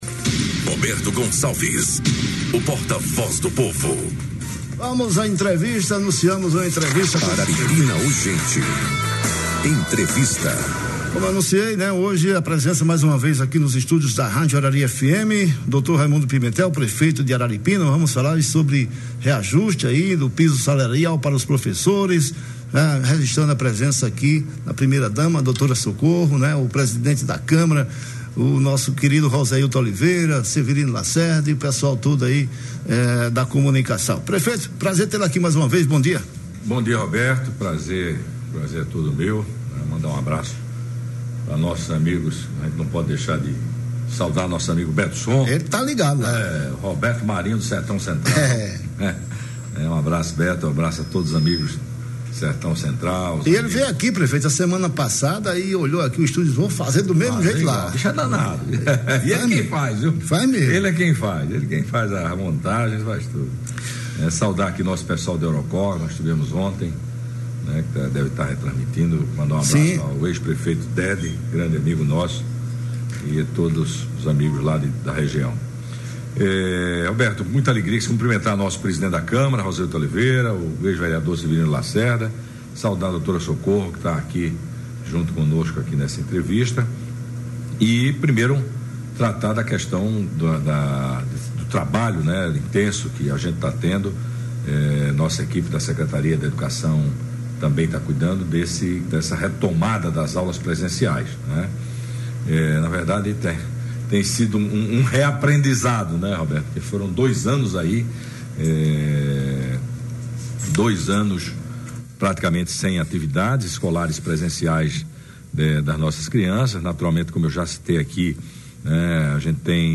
O prefeito Raimundo Pimentel anunciou nesta segunda-feira (14), na Rádio Arari FM, um reajuste salarial de 34% para garantir que todos os profissionais da rede municipal passem a receber o valor do novo piso nacional. O projeto de lei sobre o reajuste será enviado à Câmara de Vereadores para acelerar o pagamento.